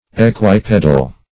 Search Result for " equipedal" : The Collaborative International Dictionary of English v.0.48: Equipedal \E*quip"e*dal\, a. [Equi- + L. pes, pedis, foot.]